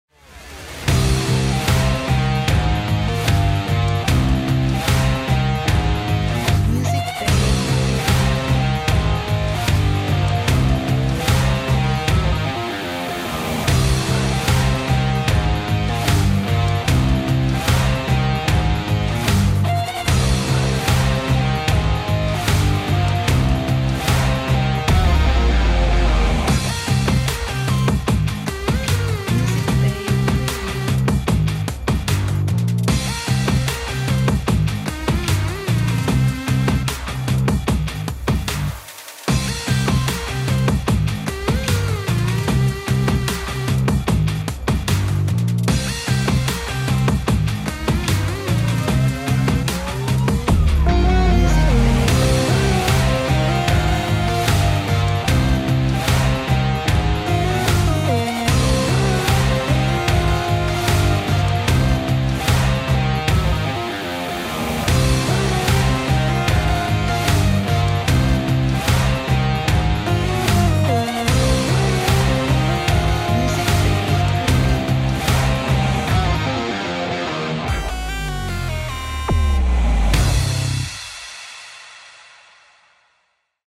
action - dynamic royalty free music for video